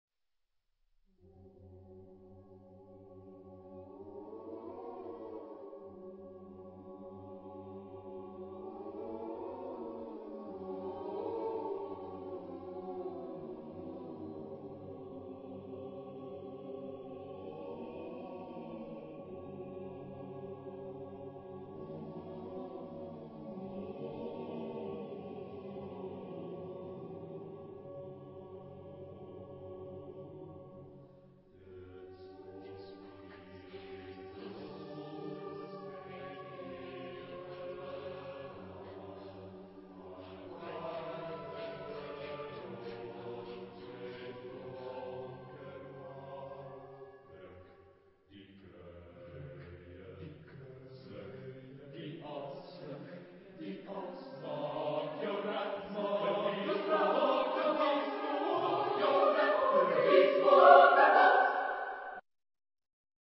Genre-Style-Form: Secular ; Contemporary ; Avant garde
Mood of the piece: haunting
Type of Choir: SATB  (4 mixed voices )
Tonality: D tonal center